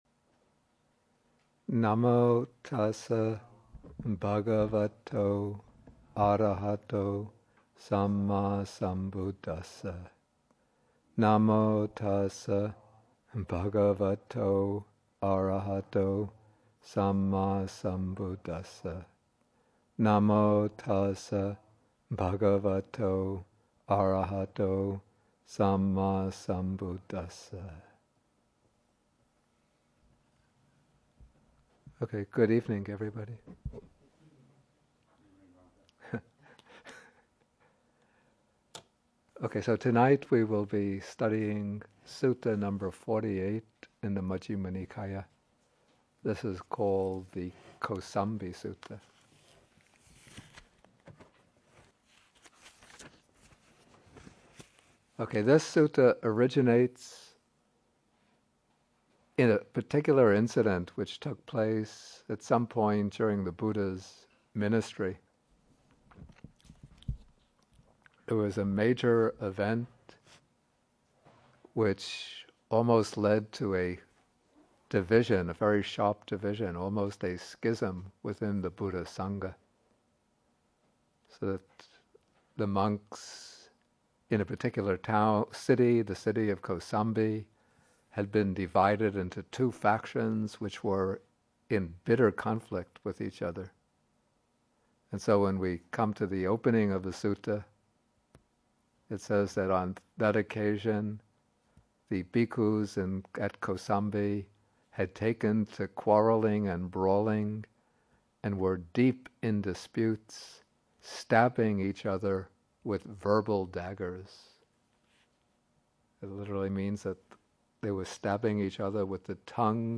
MN48, Bhikkhu Bodhi at Bodhi Monastery (lecture 136)
Lectures: